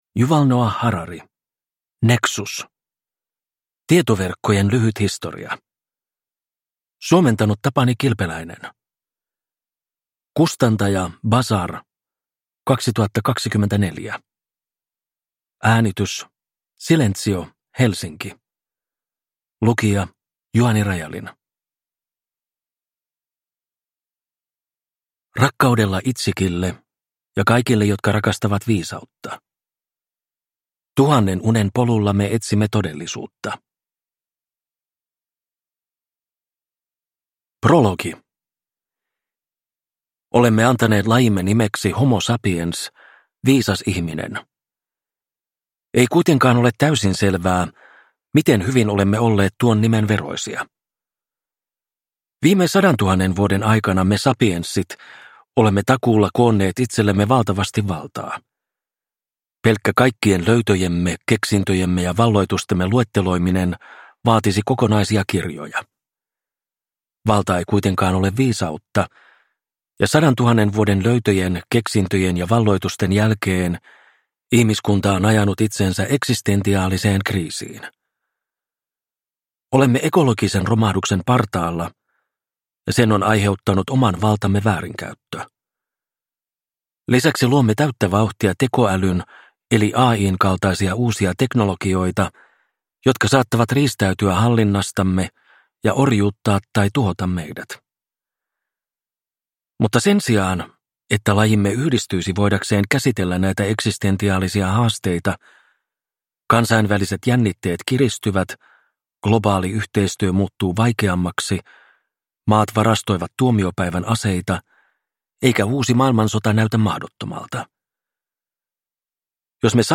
Nexus – Ljudbok